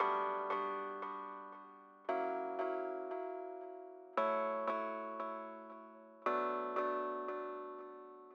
01 Chord Synth PT3.wav